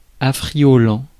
Ääntäminen
Synonyymit aimable Ääntäminen France: IPA: [a.fʁi.jɔ.lɑ̃] Haettu sana löytyi näillä lähdekielillä: ranska Käännös Ääninäyte Adjektiivit 1. lockande Suku: m .